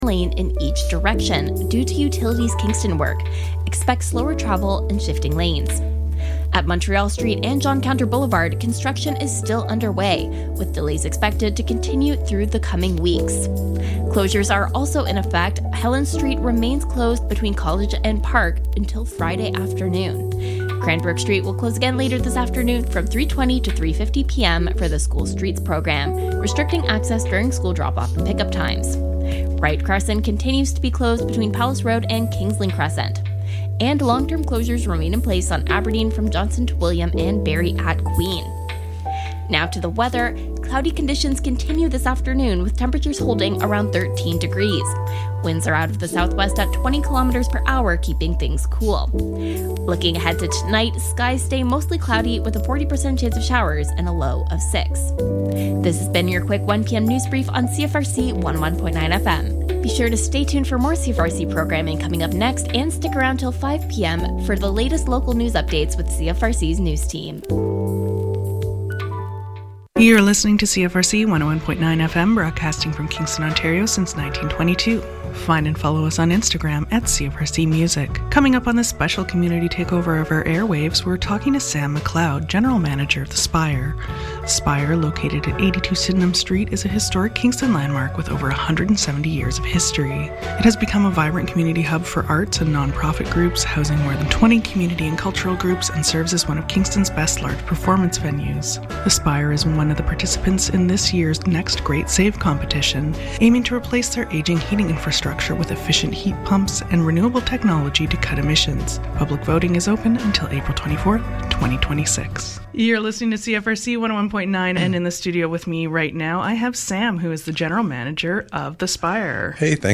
Join us on air for an hour to talk about your student group or community organization and also present your own playlist of fun music chosen by your team!